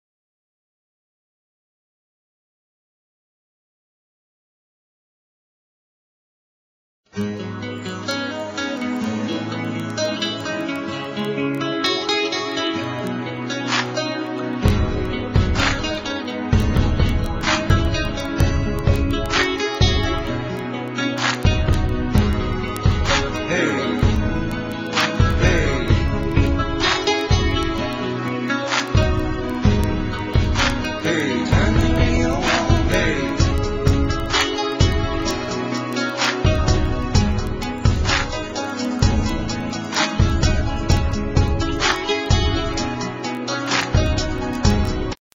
NOTE: Background Tracks 1 Thru 8